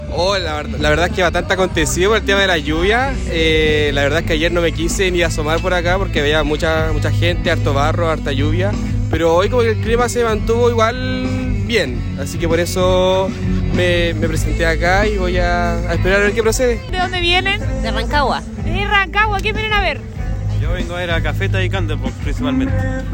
asistentes-rec-biobio.mp3